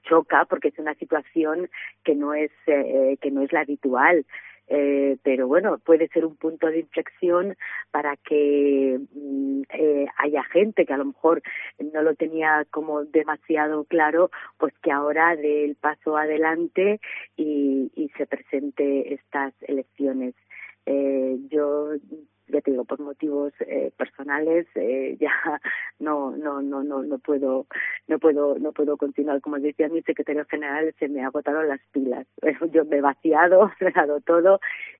La alcaldesa, Ana Agudíez, realiza para COPE sus primeras declaraciones desde que se confirmase que no concurre a la reelección y que no se han presentado listas en la localidad